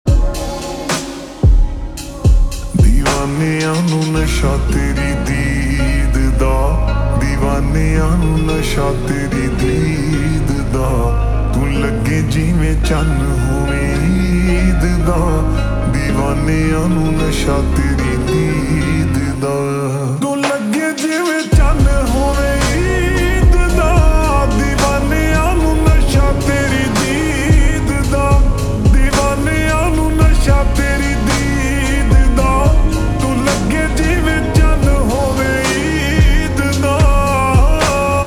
Punjabi Songs
Slow Reverb Version
• Simple and Lofi sound
• Crisp and clear sound